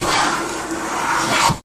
Planers | Sneak On The Lot
Hand Planing Wood, Single Stroke